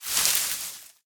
main Divergent / mods / Soundscape Overhaul / gamedata / sounds / material / human / step / bushes1.ogg 13 KiB (Stored with Git LFS) Raw Permalink History
bushes1.ogg